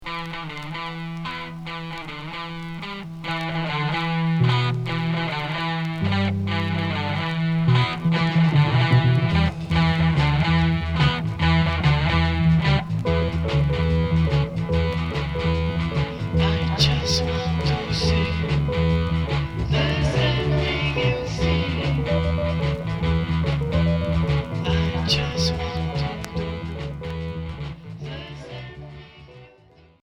Progressif Premier 45t retour à l'accueil